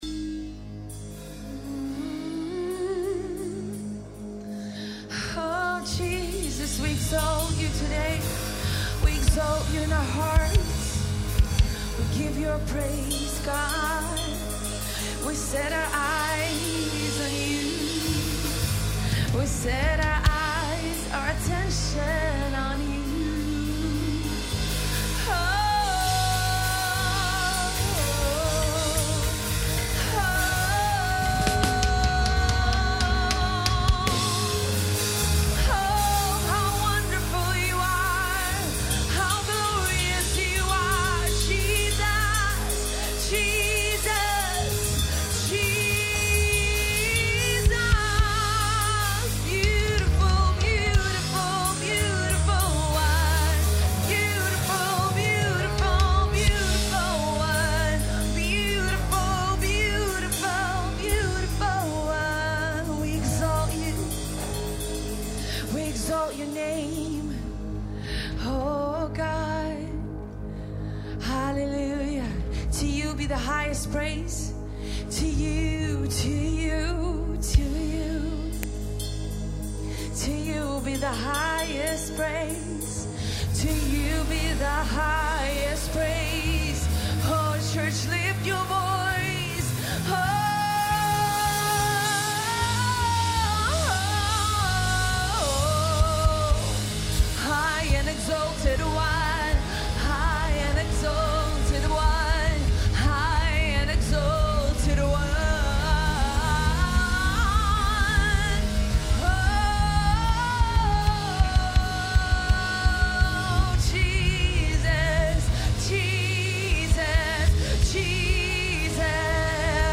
TC_Band_Live_Worship_December_6_2015.mp3